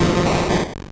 Cri de M. Mime dans Pokémon Or et Argent.